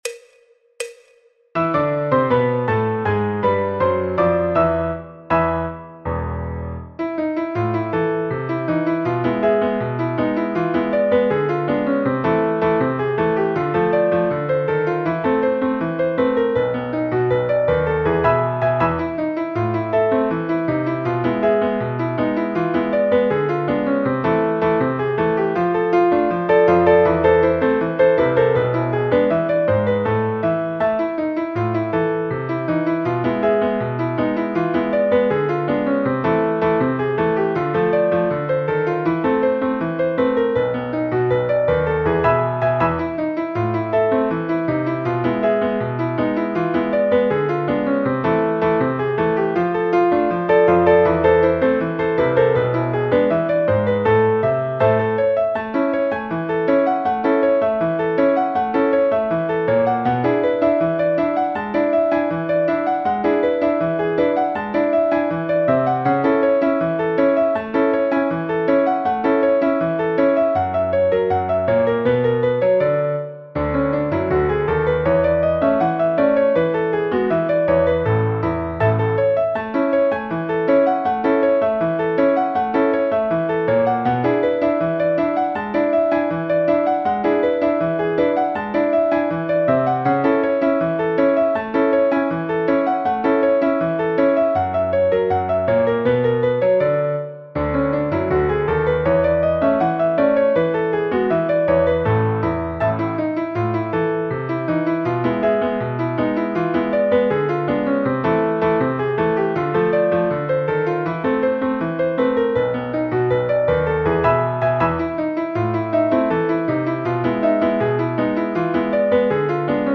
en La menor y La Mayor
Choro, Jazz, Popular/Tradicional